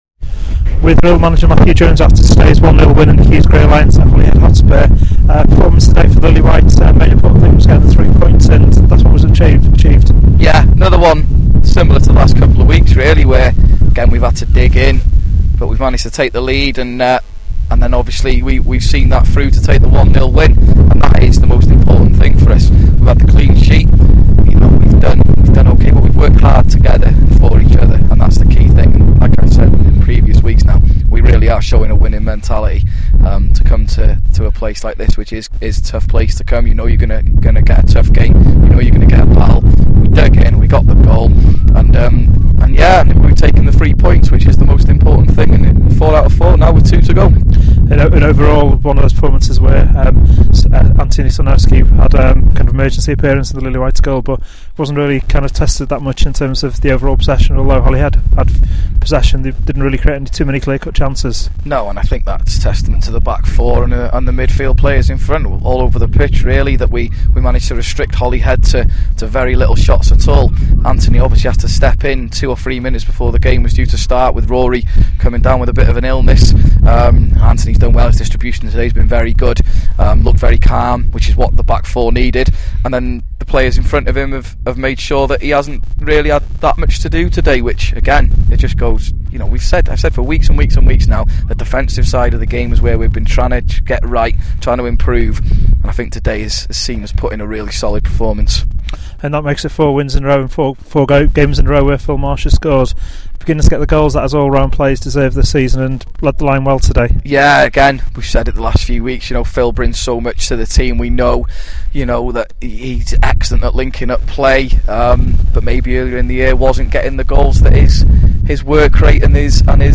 Post Match reaction
after a 1-0 win away to Holyhead at a windy New Oval